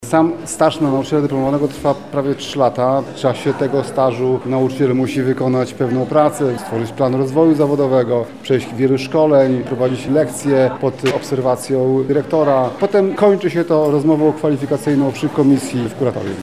Dziś (29 sierpnia) odbyło się uroczyste wręczenie aktów nadania stopnia nauczyciela dyplomowanego nauczycielom, którzy od 25 czerwca do 21 sierpnia uczestniczyli w postępowaniu kwalifikacyjnym na stopień nauczyciela dyplomowanego i uzyskali akceptację komisji kwalifikacyjnej.
Nauczyciel dyplomowany jest najlepszym fachowcem w swojej dziedzinie, to jest najwyższy level jaki może osiągnąć – mówi Lubelski Kurartor Oświaty Tomasz Szabłowski, tłumacząc drogę, jaką musi przejść pedagog, w celu uzyskania takiego tytułu: